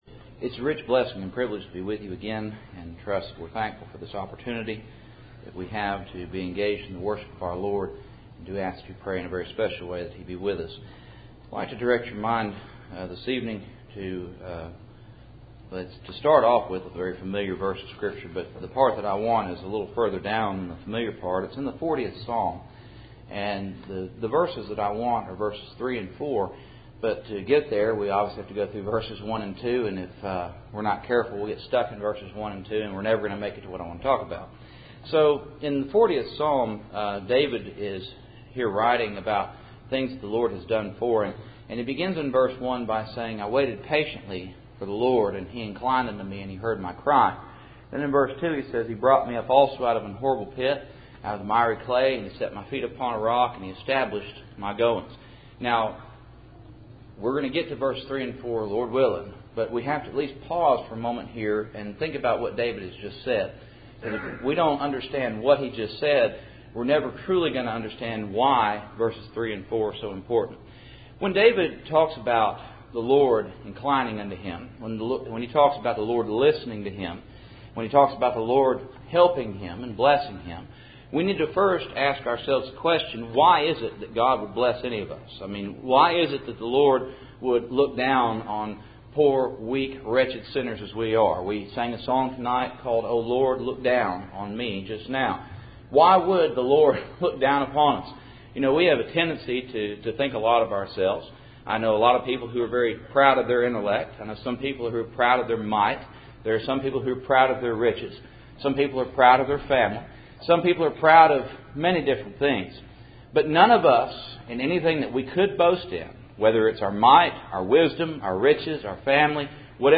Service Type: Cool Springs PBC Sunday Evening